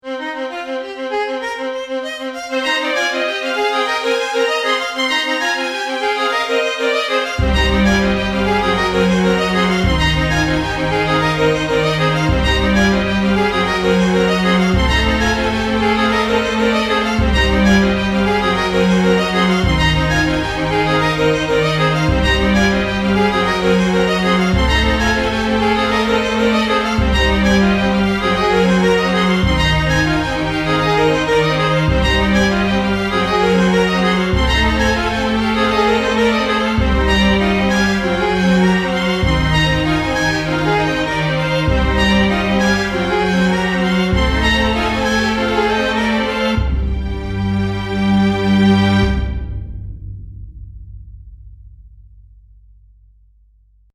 Musica orquestal cinematográfica
cinematográfico
orquestal
rítmico